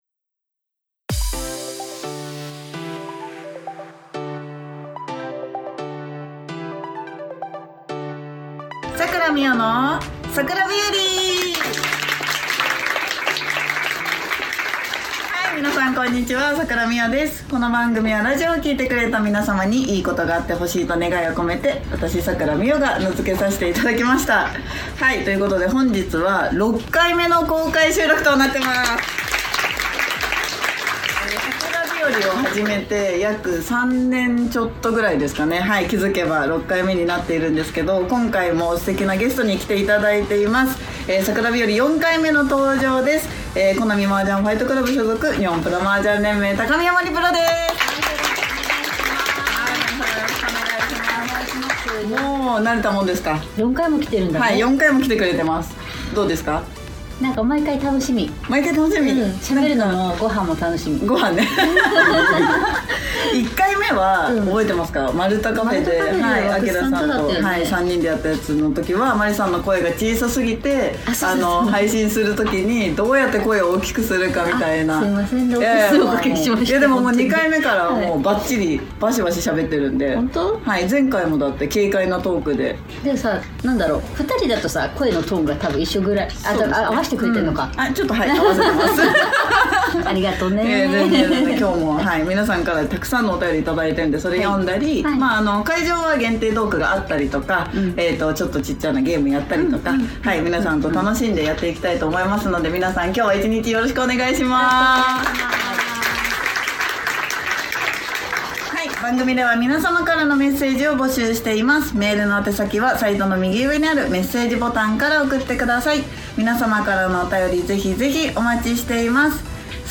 約8ヶ月ぶりの公開収録です！今回のゲストは大人気プロ雀士の高宮まりさん♪沢山の方にお越しいただき本当にありがとうございました！！